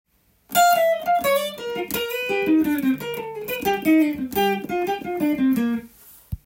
②のフレーズは、E♭M7の代理コードであるGm7を使い
E♭ｍM7を入れてジャズっぽいフレーズになっています。